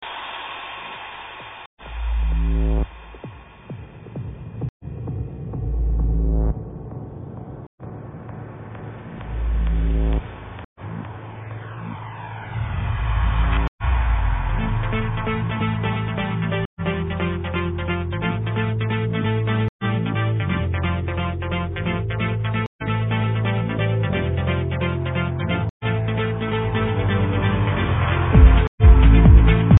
PleaseID this 3rd Trance Music It's an intro